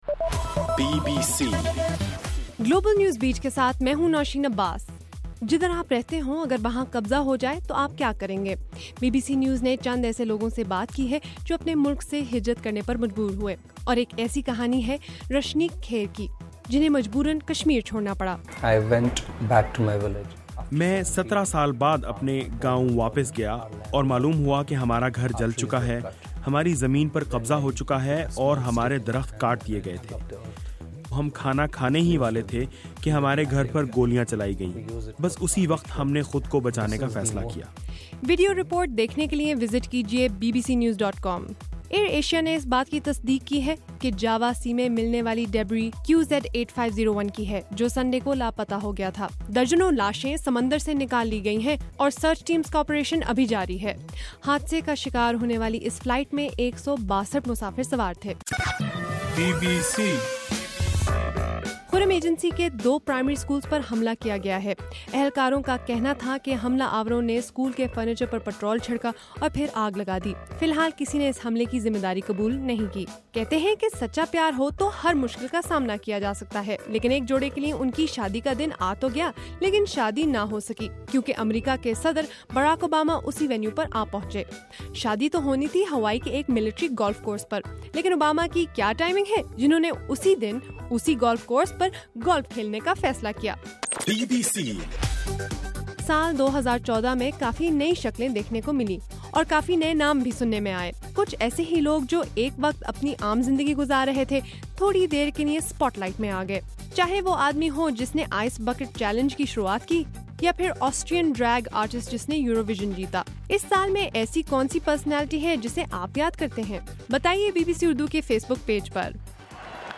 دسمبر 31: صبح 1 بجے کا گلوبل نیوز بیٹ بُلیٹن